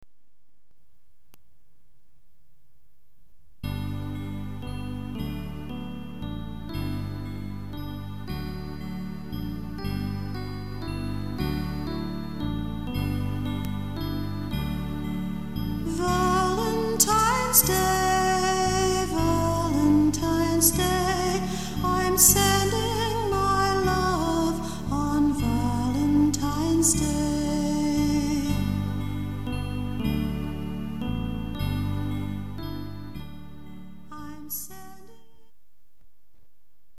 CHILDREN'S SONGS